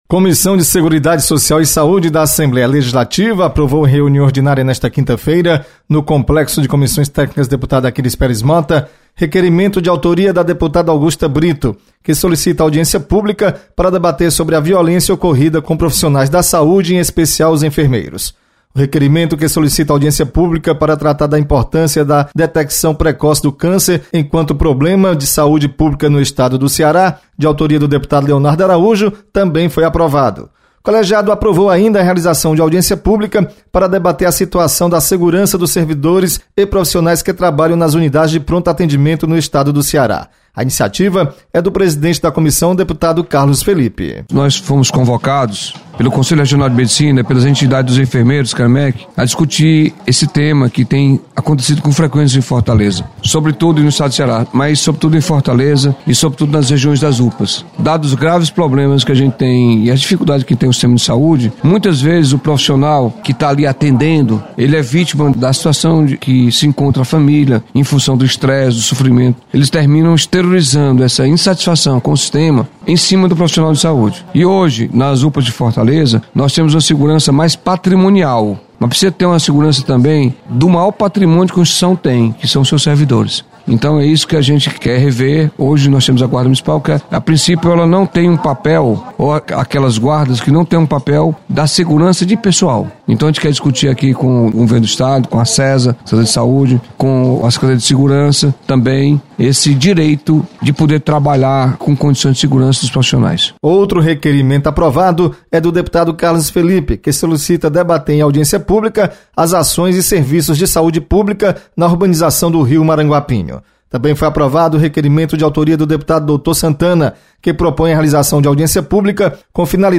Comissões realizam reunião nesta quinta-feira. Repórter